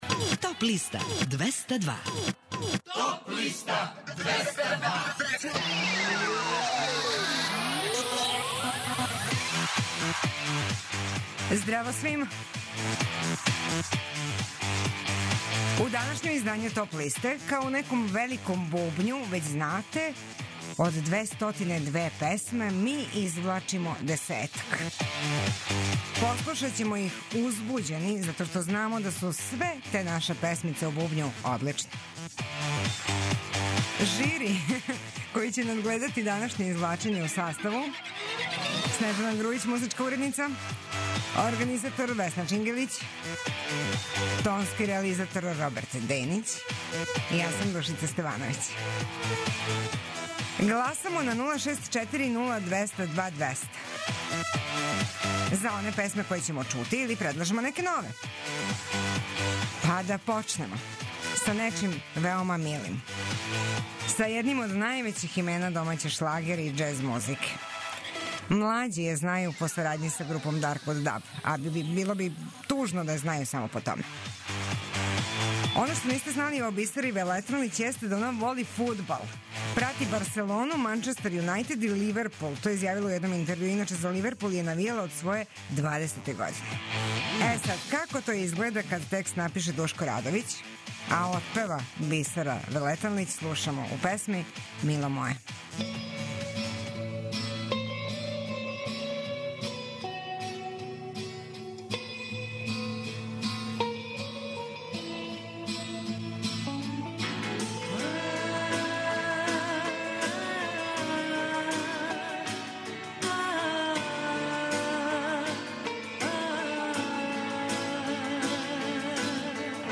преузми : 25.66 MB Топ листа Autor: Београд 202 Емисија садржи више различитих жанровских подлиста.